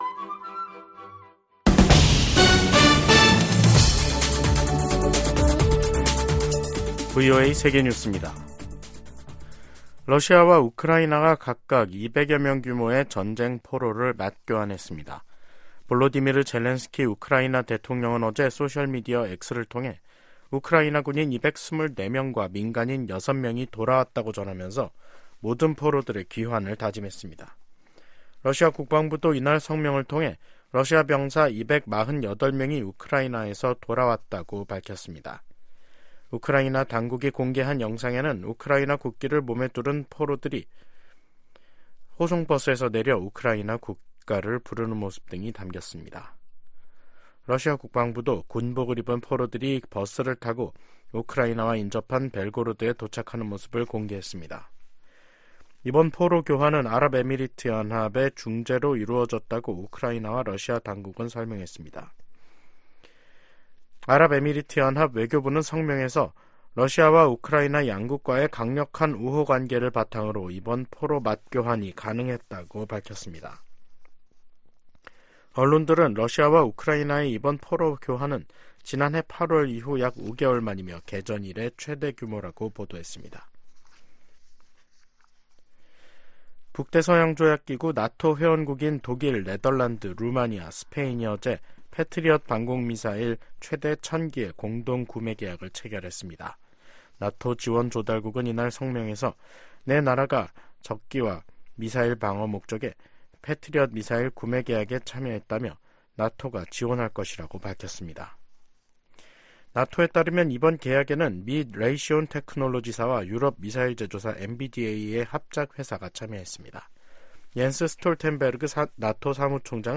VOA 한국어 간판 뉴스 프로그램 '뉴스 투데이', 2024년 1월 4일 2부 방송입니다. 미국은 북한과의 대화에 큰 기대는 않지만 여전히 환영할 것이라고 국무부 대변인이 말했습니다. 김정은 북한 국무위원장이 남북관계를 '적대적 두 국가 관계'로 선언한 이후 북한은 대남노선의 전환을 시사하는 조치들에 나섰습니다. 23일로 예정된 중국에 대한 유엔의 보편적 정례인권검토(UPR)를 앞두고 탈북민 강제북송 중단 압박이 커지고 있습니다.